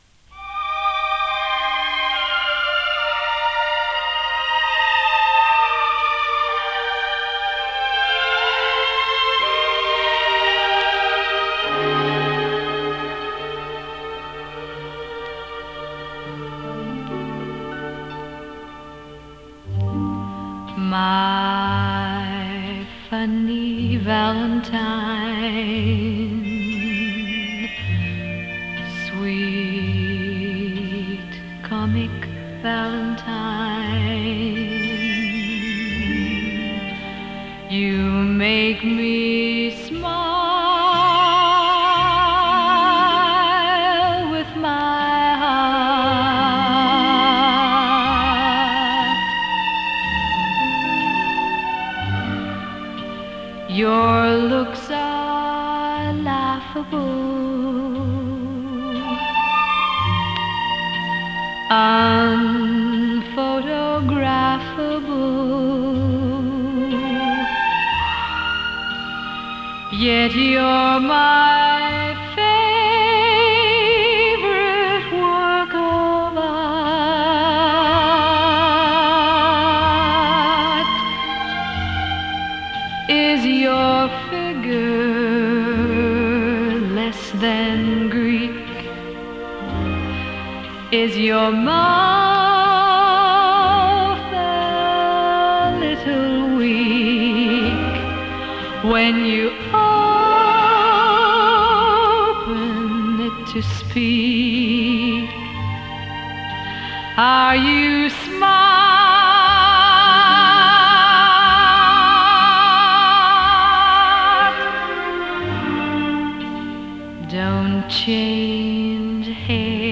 ラテン味
ビッグバンド ジャズボーカル